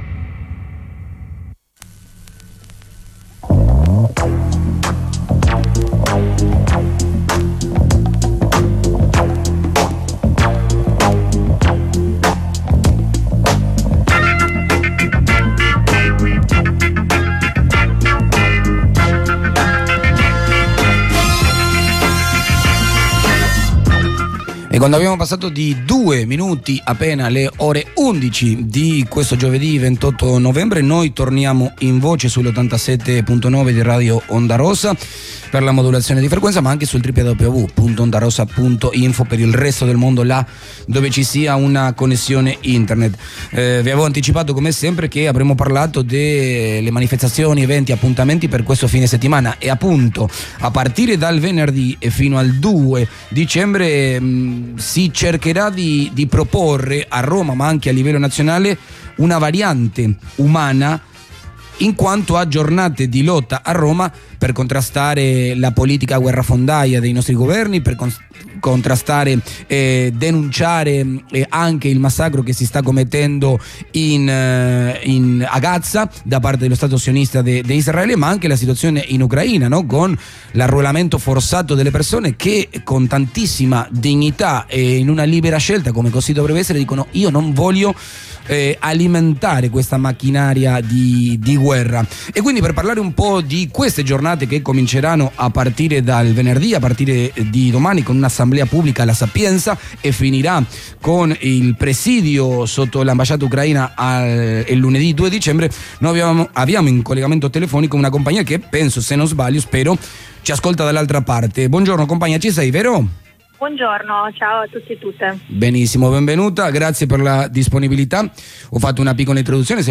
Interviene una compagna sulla manifestazione di Milano